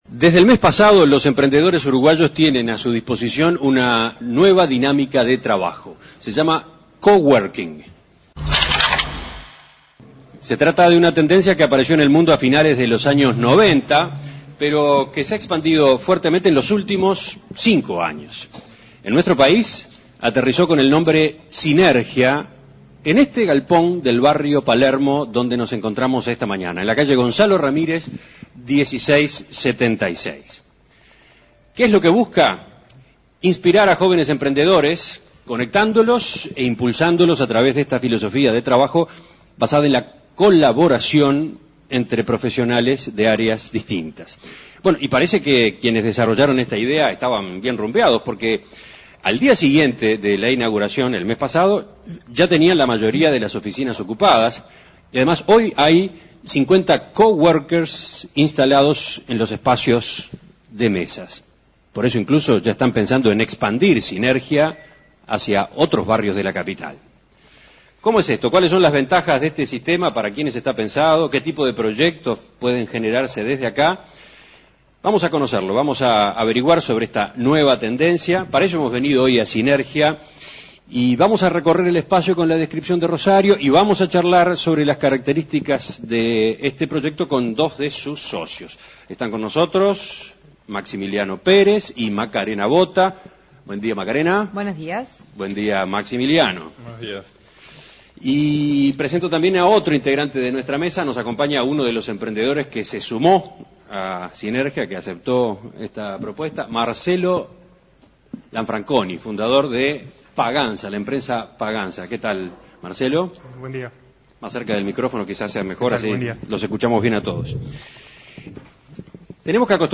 En el barrio de Palermo, en Montevideo, se inauguró un espacio para trabajar en equipo: Sinergia Cowork es el primer espacio de coworking en el Uruguay. La idea es que los emprendedores que recién están empezando a poner en práctica sus ideas tengan un lugar para trabajar e interactuar con otras personas. En Perspectiva se trasladó hacia el lugar y conversó con dos de sus socios